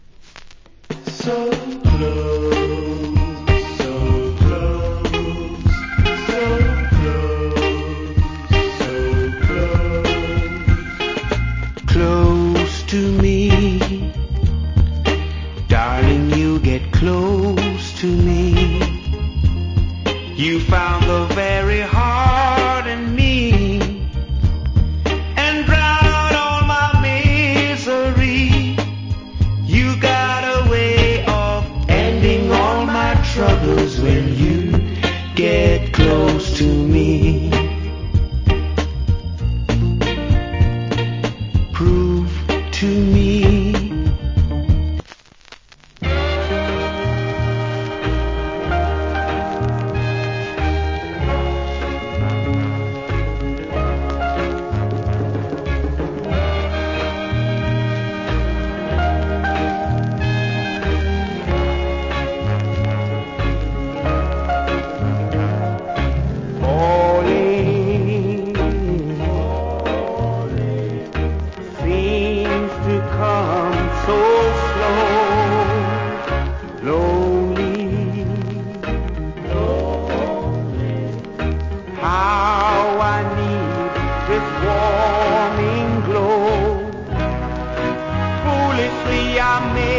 Nice Soulful Vocal.